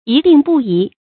一定不移 注音： ㄧˊ ㄉㄧㄥˋ ㄅㄨˋ ㄧˊ 讀音讀法： 意思解釋： 見「一定不易」。